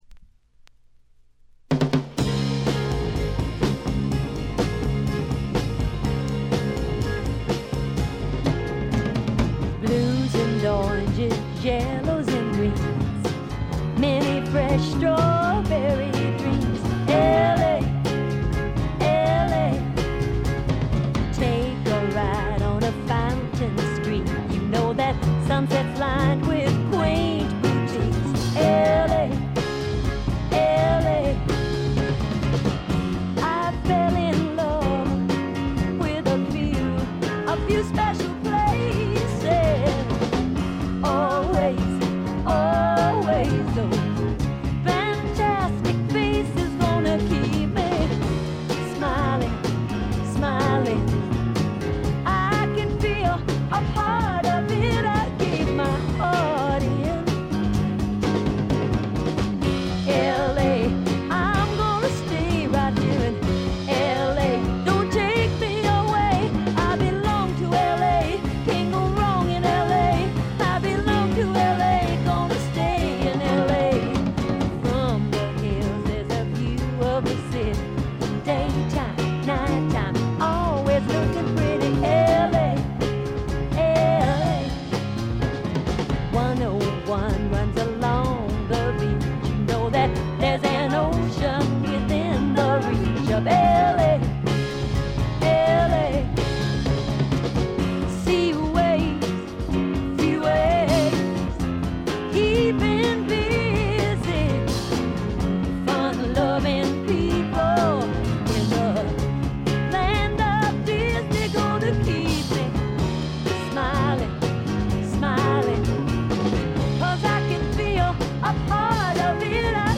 ところどころでチリプチ、プツ音。
試聴曲は現品からの取り込み音源です。